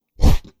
Close Combat Swing Sound 26.wav